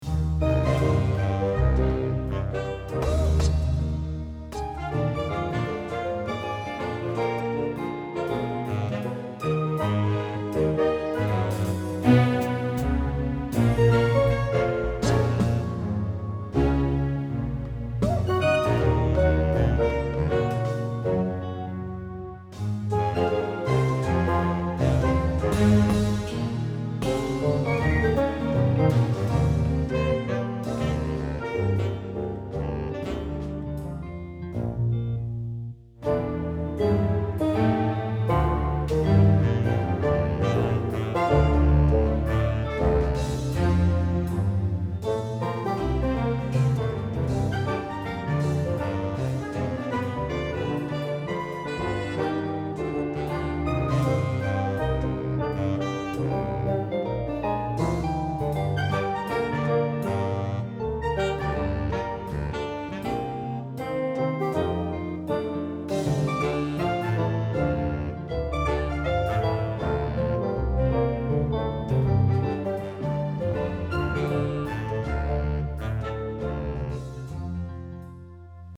Orchestra & Combo